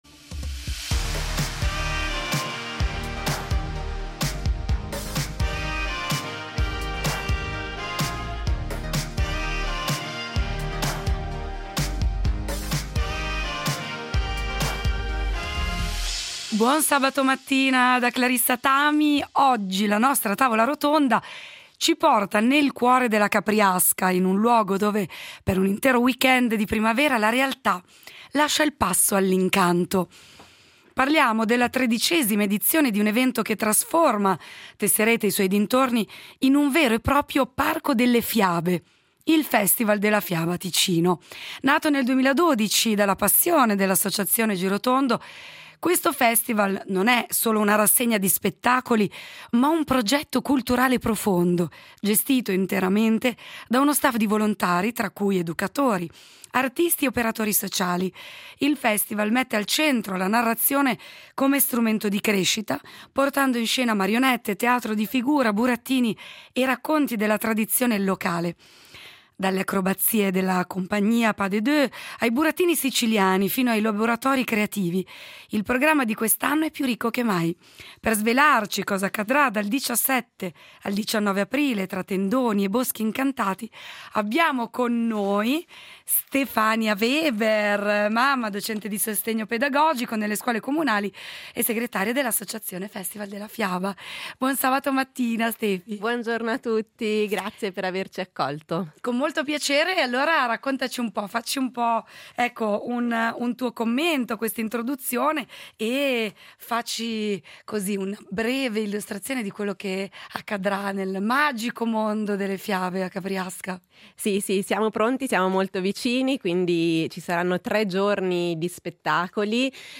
In studio le voci di Festival della fiaba .